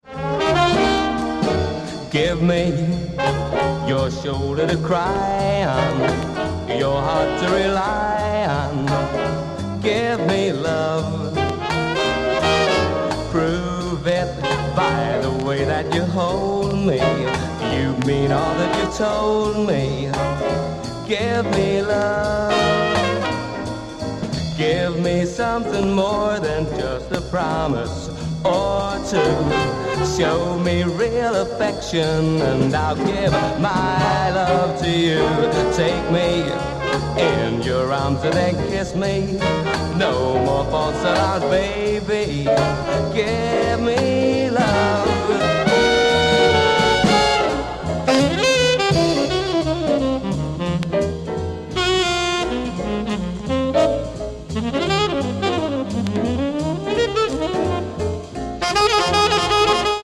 afro jazz